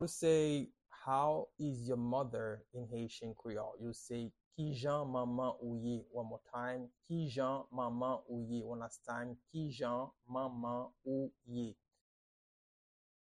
Pronunciation and Transcript:
How-is-your-mother-in-Haitian-Creole-–-Kijan-manman-ou-ye-pronunciation-by-a-Haitian-teacher.mp3